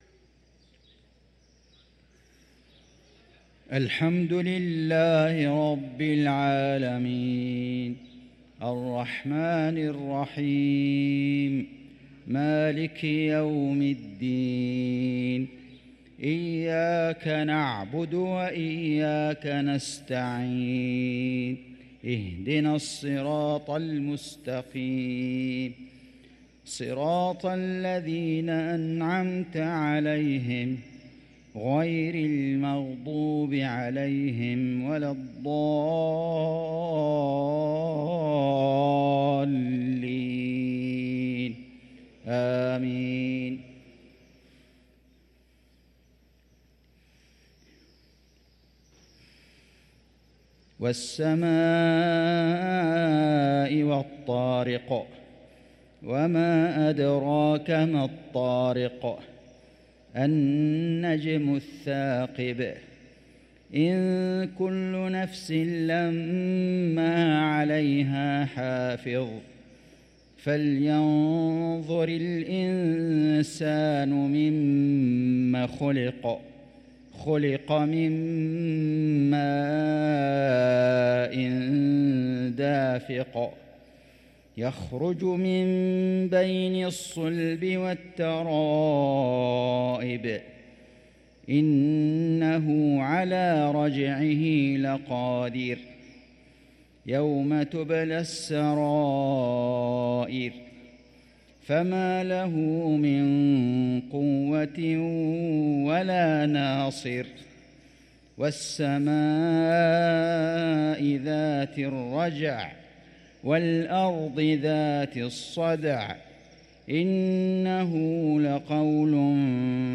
صلاة المغرب للقارئ فيصل غزاوي 21 جمادي الآخر 1445 هـ
تِلَاوَات الْحَرَمَيْن .